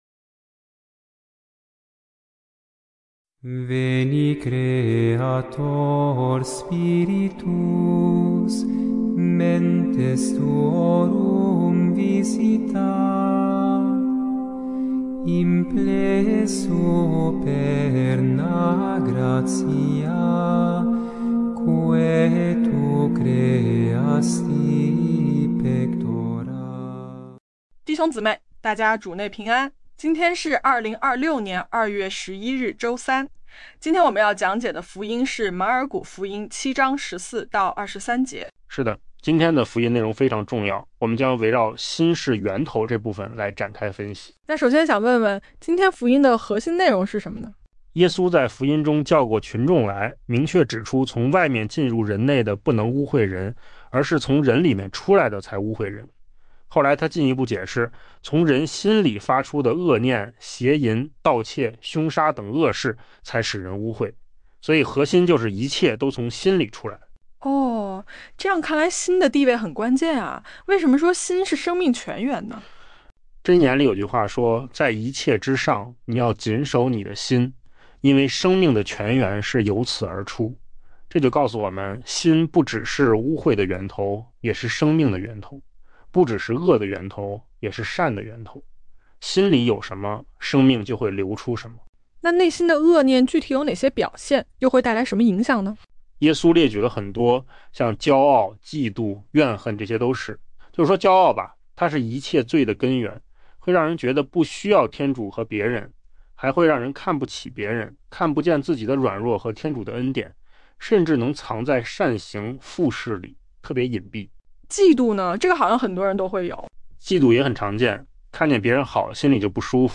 首页 / 证道/ 每日圣言讲解